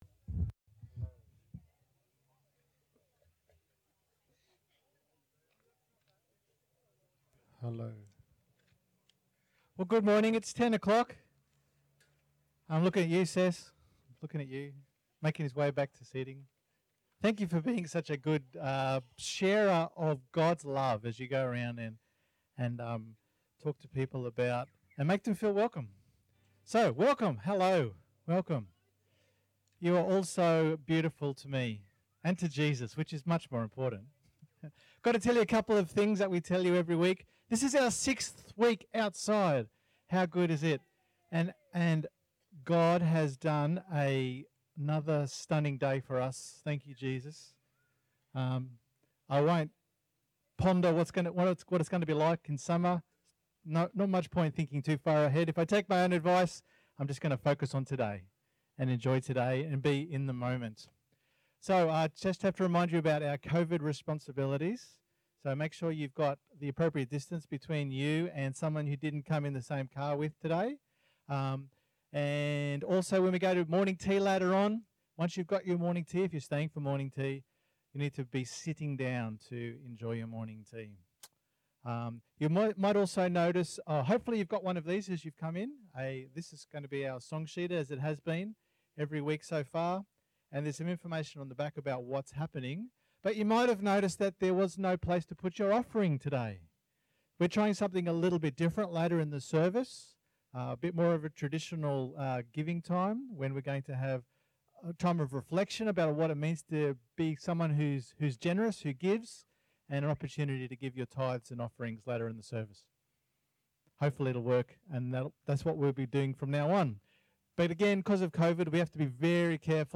Sorry for the sound quality.